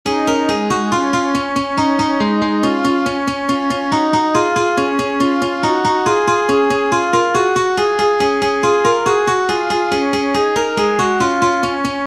MP3] This simple example illustrates how one can join several music 'tracks'.